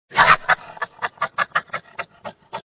Голос барсука: звуки и особенности